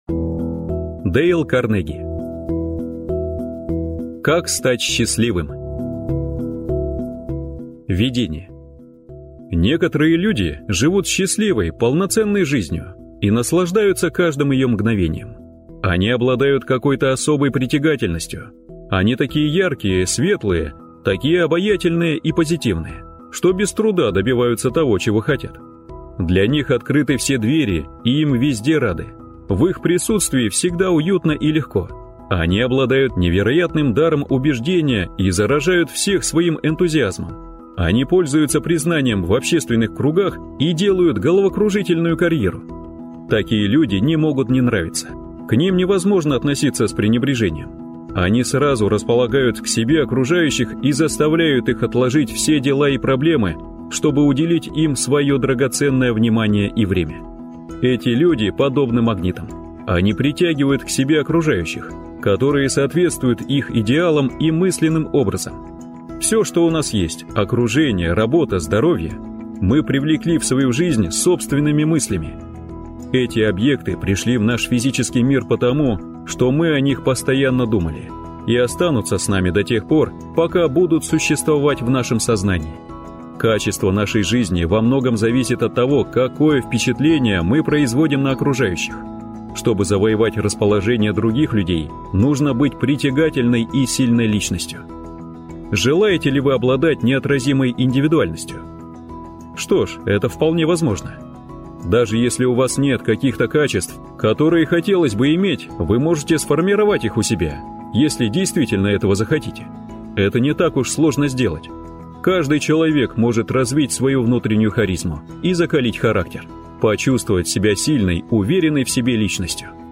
Аудиокнига Как стать счастливым | Библиотека аудиокниг